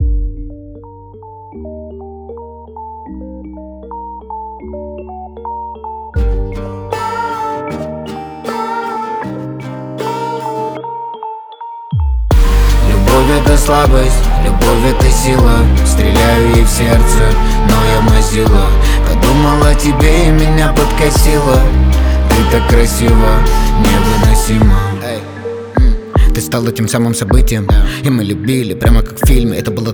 Russian Pop